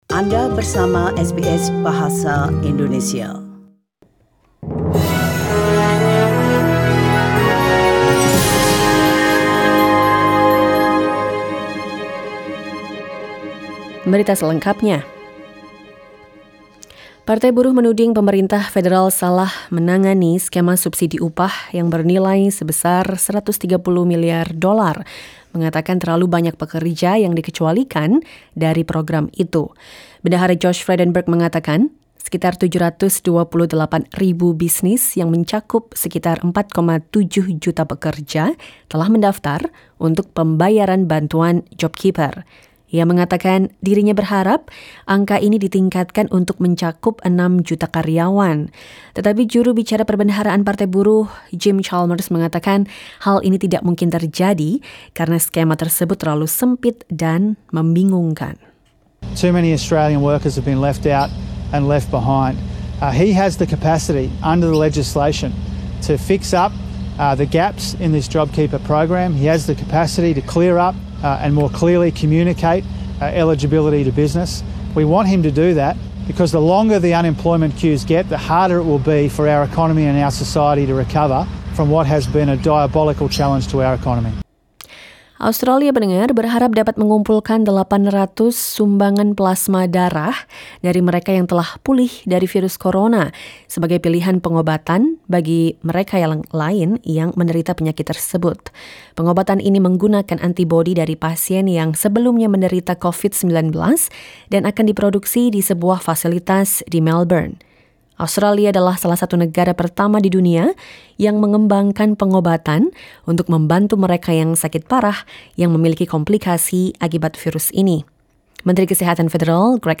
SBS Radio news in Indonesian - 6 May 2020